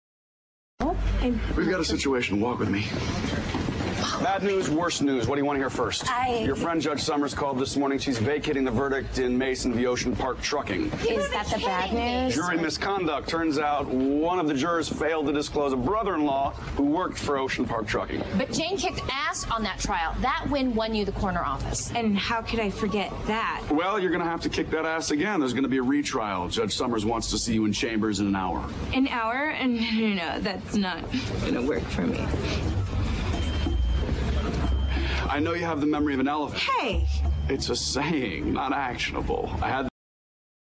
在线英语听力室影视剧中的职场美语 第53期:晴天霹雳的听力文件下载,《影视中的职场美语》收录了工作沟通，办公室生活，商务贸易等方面的情景对话。每期除了精彩的影视剧对白，还附有主题句型。